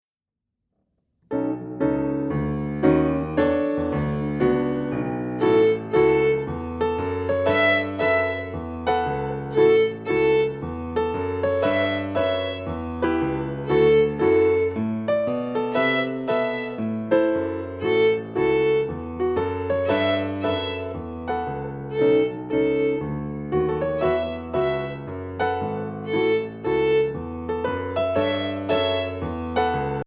Voicing: Violin Collection